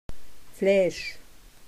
Wörterbuch der Webenheimer Mundart